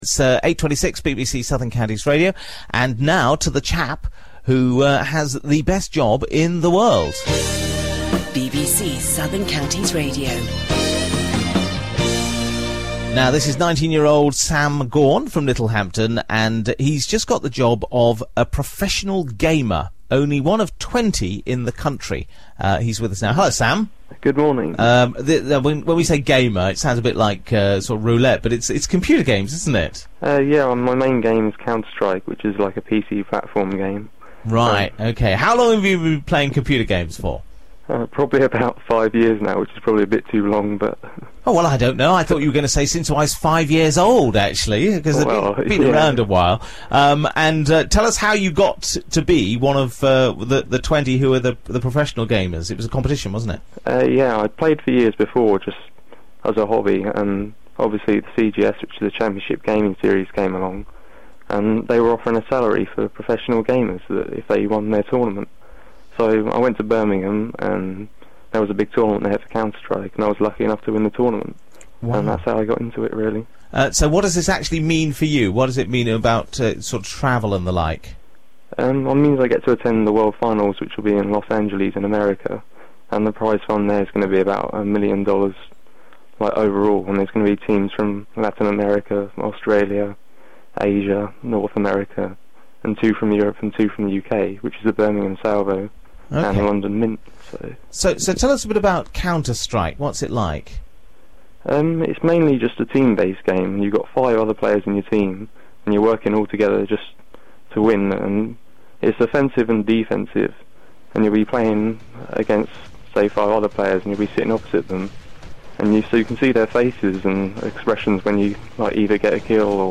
Image Version 2 - The story itself. 2007 Season Southern Counties Radio - Interview on 10th October 2007 on Southern Counties Radio.